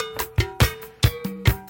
My cut was based on the rhythm (by ear) method.